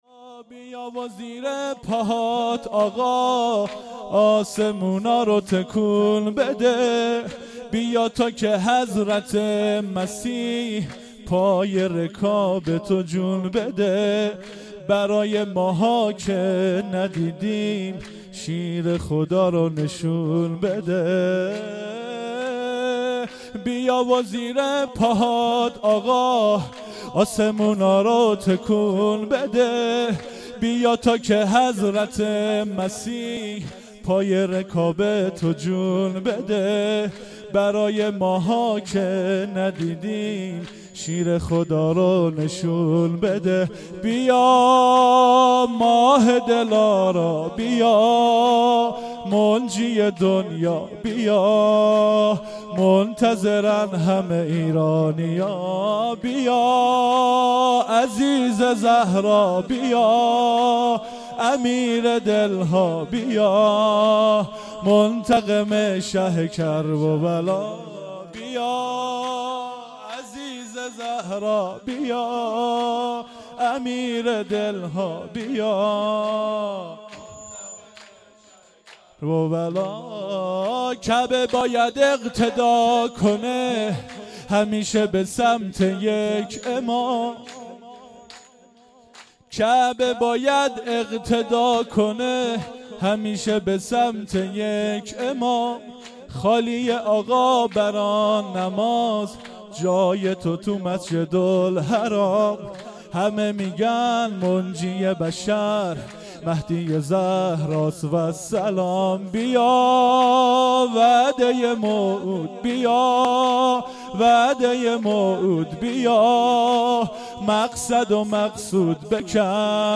جشن میلاد امام زمان عجل الله تعالی فرجه الشریف1397
سرود (1)
جشن-نیمه-شعبان-97-سرود1.mp3